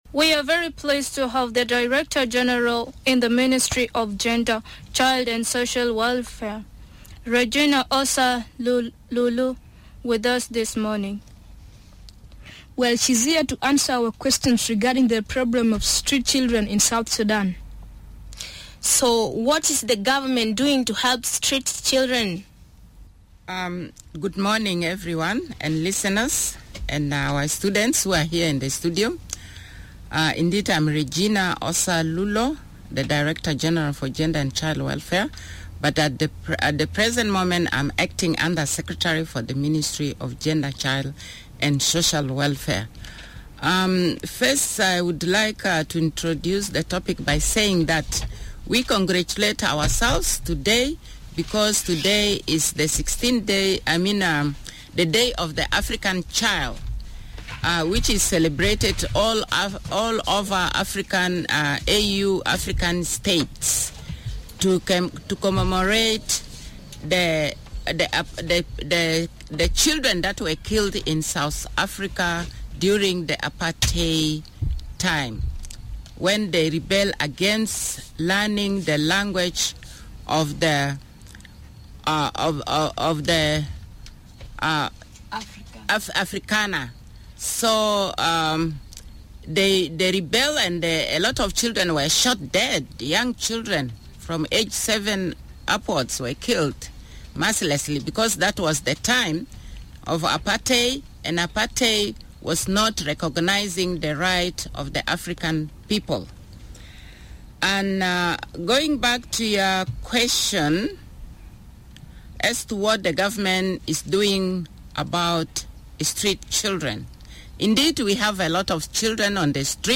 Headliner Embed Embed code See more options Share Facebook X Subscribe South Sudanese children took over the airwaves today at Radio Miraya. They had lots of questions for the Director General at the Ministry of Gender, Child and Social Welfare, Regina Ossa about street children, the role of the government in helping them, and how children are being protected from forced labor.